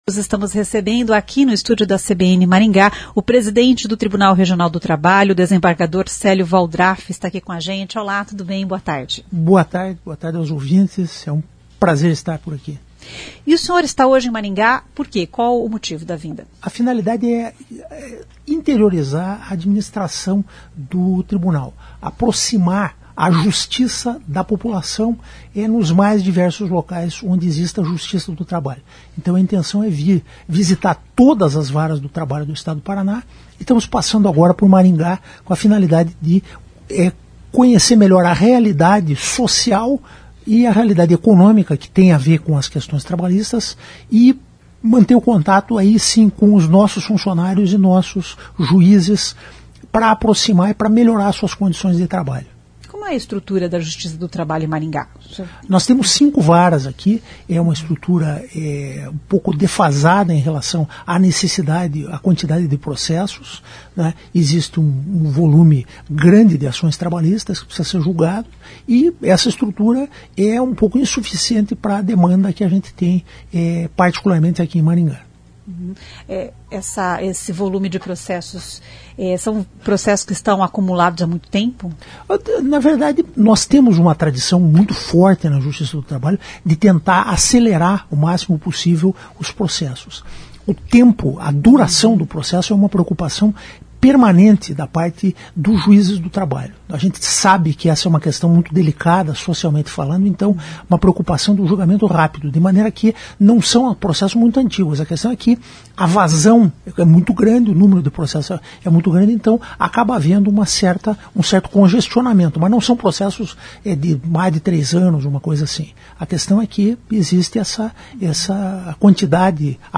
O desembargador Célio Waldraff, presidente do Tribunal Regional do Trabalho do Paraná, diz que neste ano de eleições a Justiça do Trabalho está fazendo um alerta aos empregadores sobre assédio eleitoral para evitar o que ocorreu na última eleição, em 2022. O assédio eleitoral ocorre quando o empregador constrange o empregado para forçá-lo a votar em um determinado candidato.